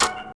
SFX
LADDER3.mp3